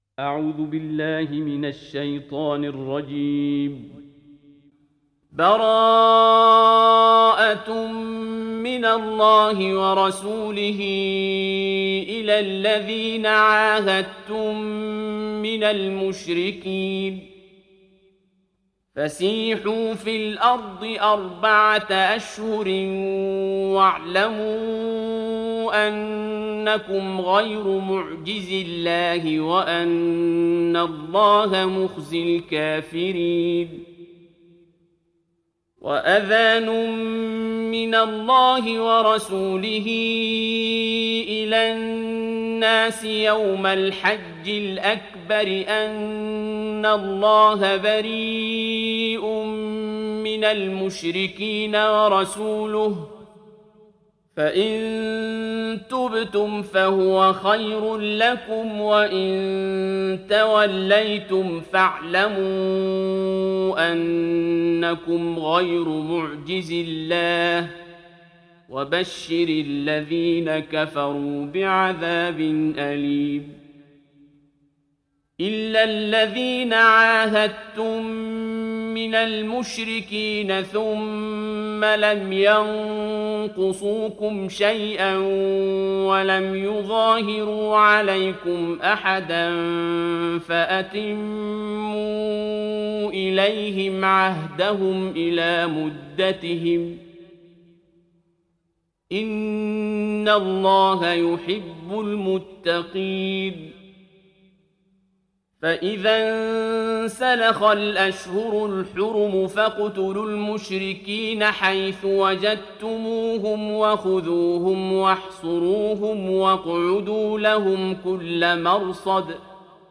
دانلود سوره التوبه mp3 عبد الباسط عبد الصمد روایت حفص از عاصم, قرآن را دانلود کنید و گوش کن mp3 ، لینک مستقیم کامل